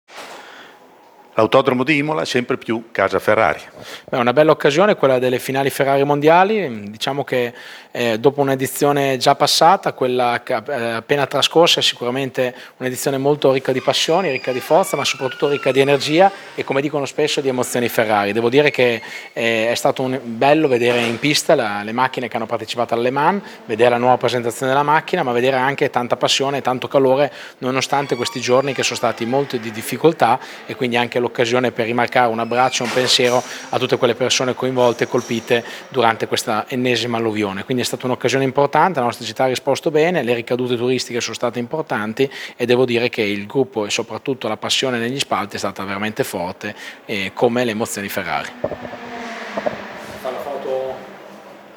Marco Panieri, sindaco di Imola, intervistato